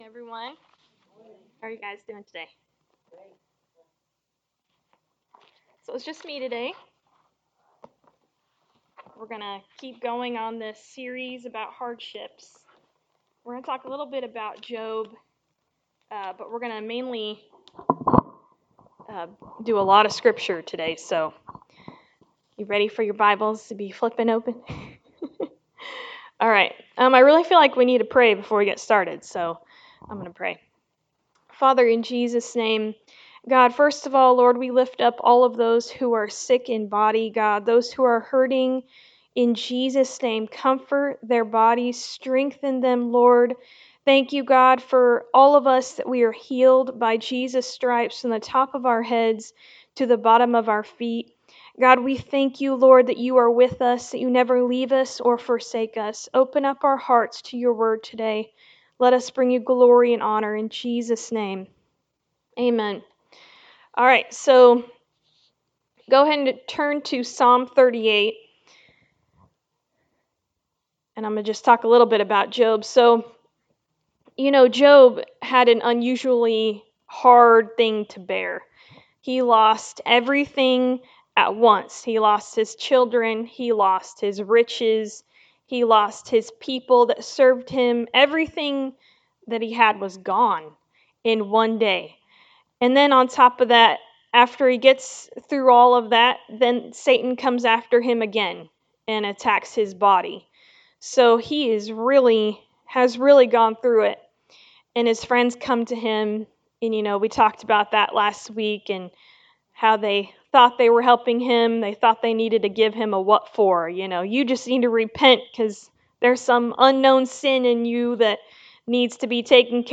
Suffering – Sermon 3 from the Hardships Series
Service Type: Sunday Morning Service